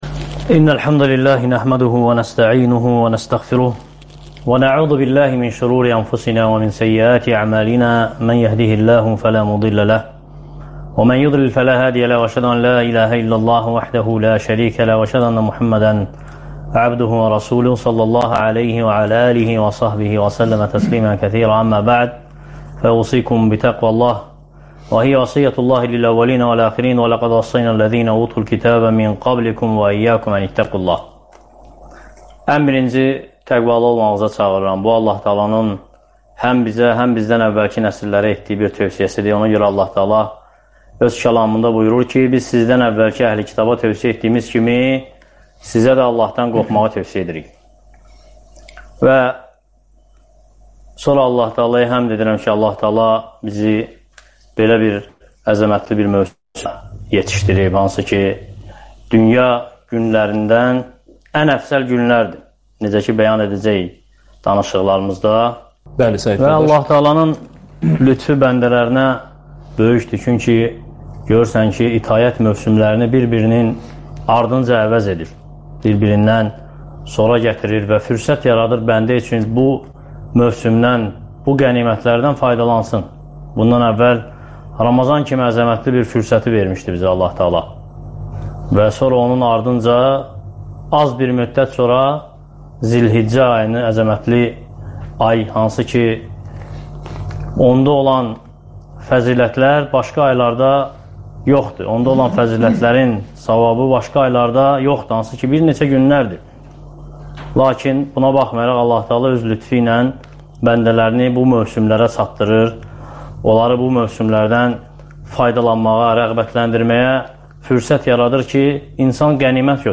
Zül-hiccə ayı və sual-cavab